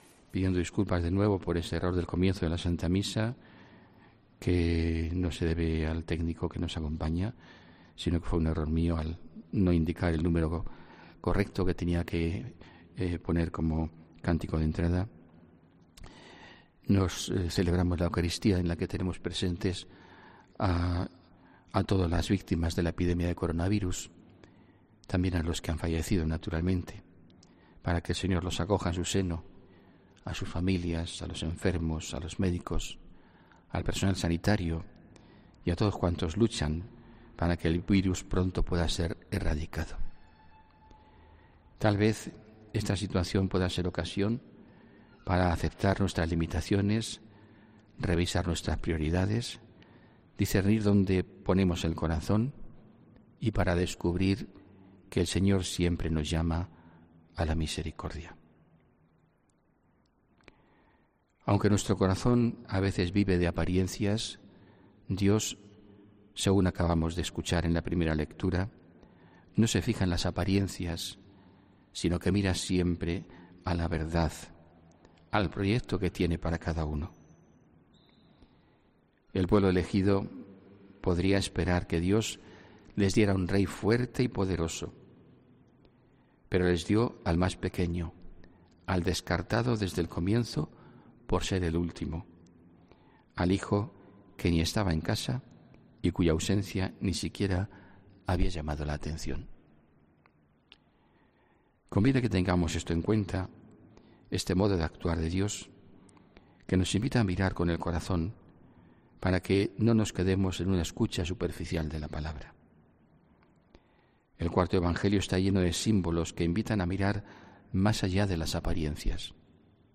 HOMILÍA 22 MARZO 2020